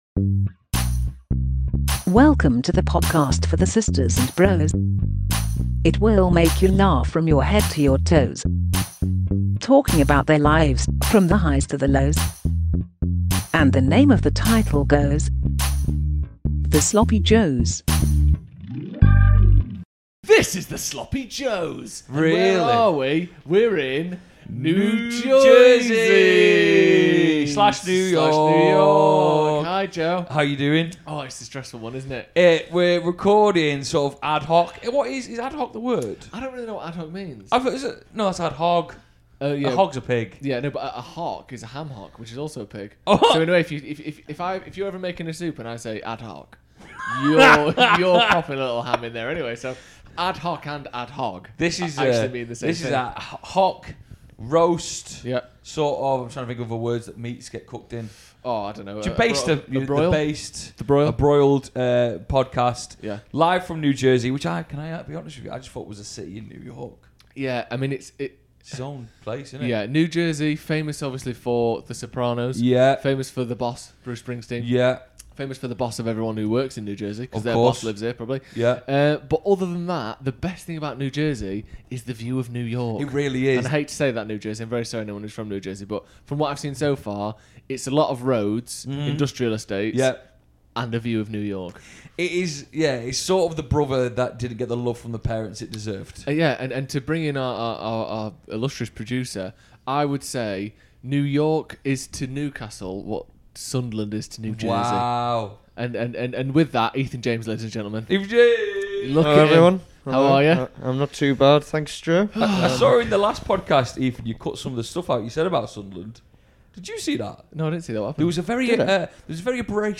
Join us for a PACKED show recorded in the US.